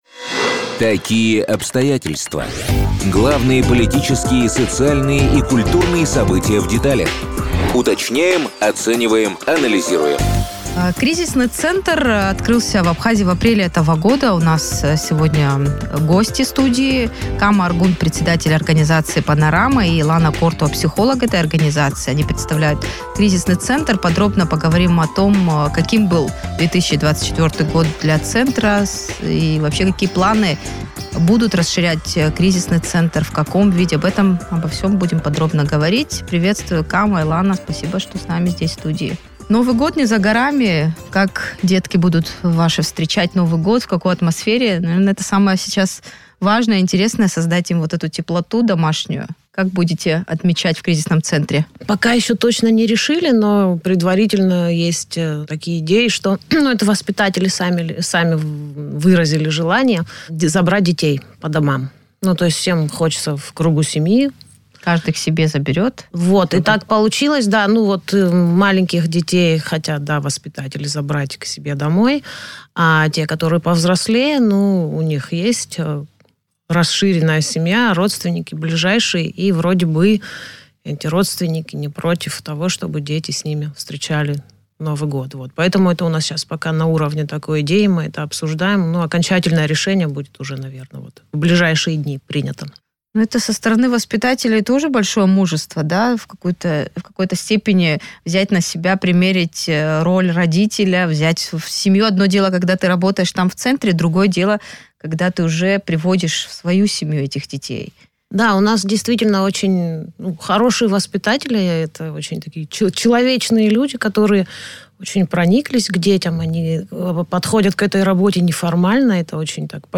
в интервью радио Sputnik